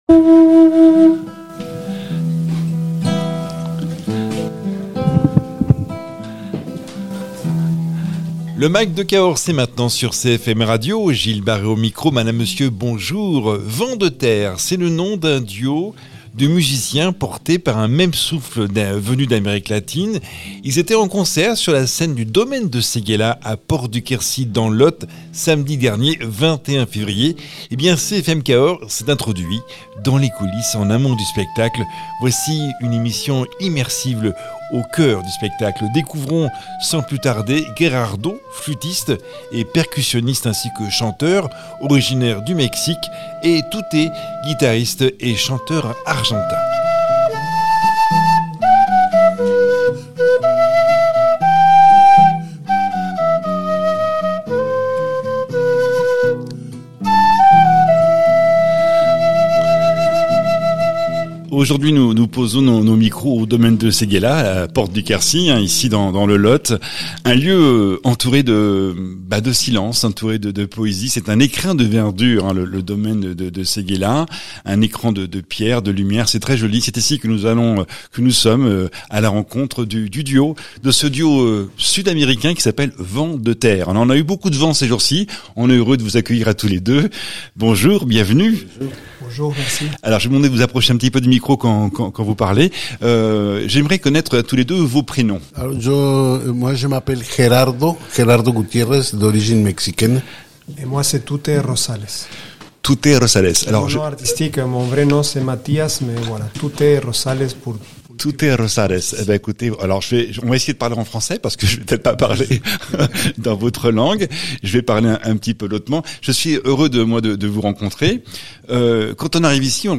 Ils seront en concert au Domaine de Séguéla, à Porte-du-Quercy (Lot), samedi 21 février. CFM Cahors a réalisé une émission immersive en amont du concert.